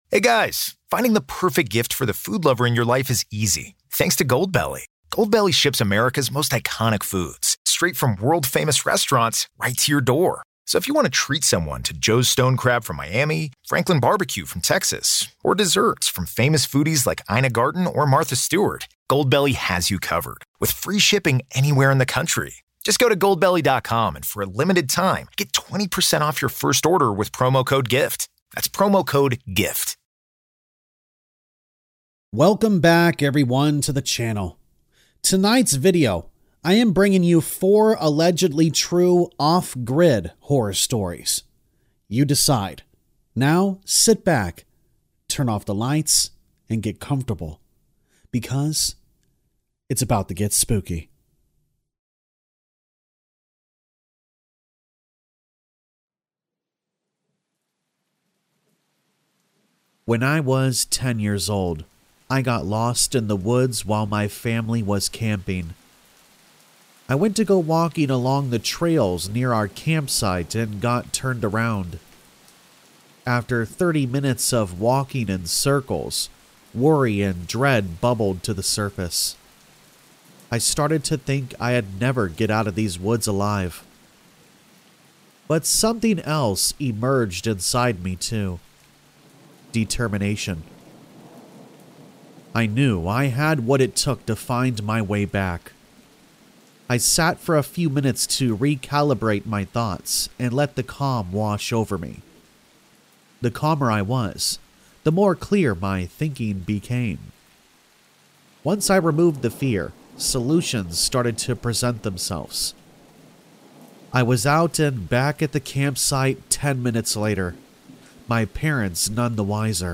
4 TRUE Off Grid Horror Stories | Black Screen For Sleep | Forest Camping Stories With Ambient Rain
All Stories are read with full permission from the authors: